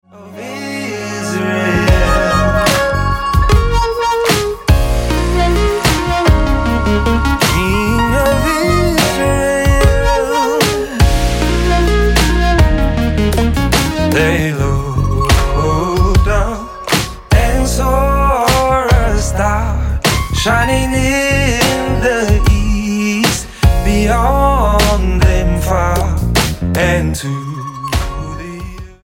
STYLE: Pop
male-led
with an unexpected rap section is fun